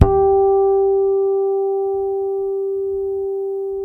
HARMONICS HI.wav